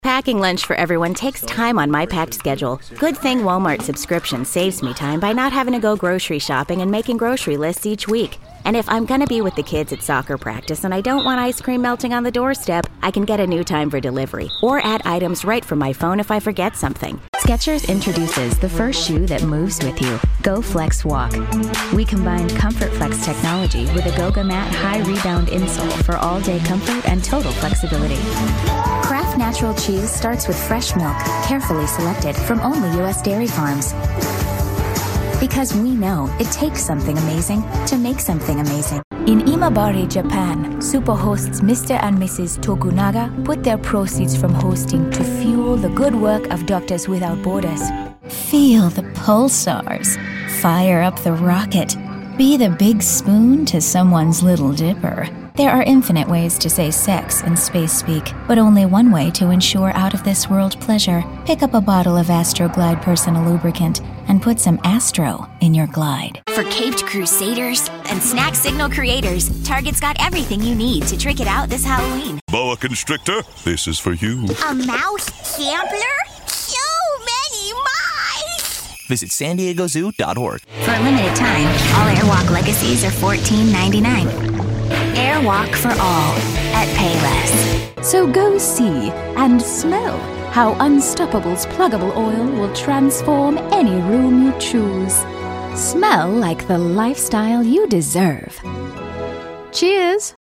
English (American)
Broadcast quality home studio.
HighMezzo-SopranoSopranoVery High
FriendlyConversationalConfidentEducatedArticulateDynamicCorporateExperiencedRelatableBubblyBrightApproachableUpbeat